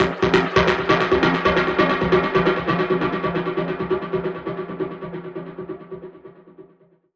Index of /musicradar/dub-percussion-samples/134bpm
DPFX_PercHit_E_134-09.wav